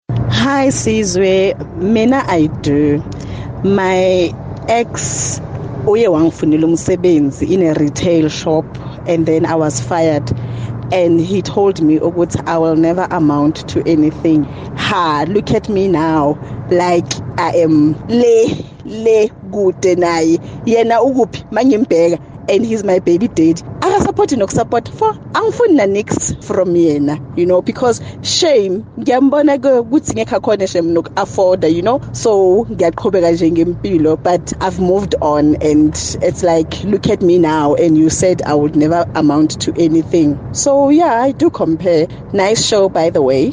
Here’s how Kaya Drive listeners felt about comparisons with their ex’s new partner: